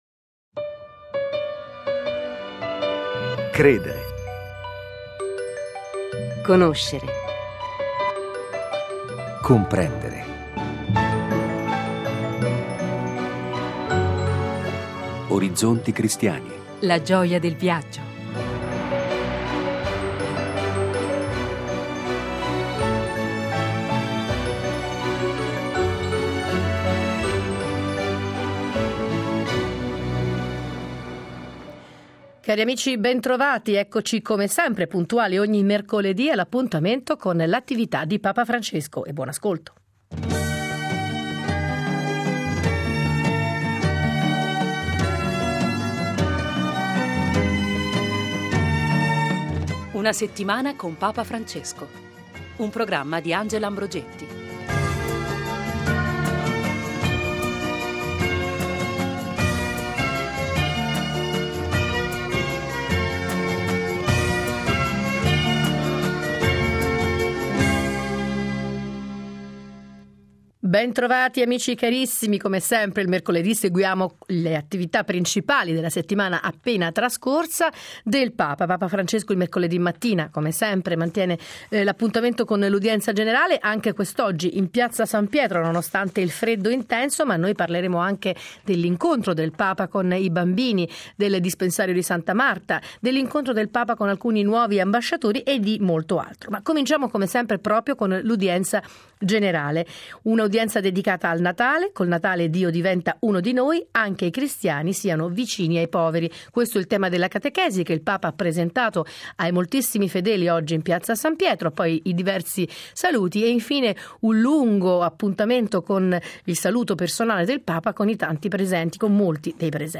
mercoledì 18 mercoledì Venticinque minuti ogni settimana per riascoltare i discorsi, rivivere gli incontri, raccontare le visite di Papa Francesco. Qualche ospite e la lettura dei giornali, ma soprattutto la voce del Papa a partire dall’appuntamento del mercoledì per l’Udienza Generale.